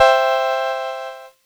Cheese Chord 06-C3.wav